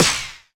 hitBaxter_Farther.wav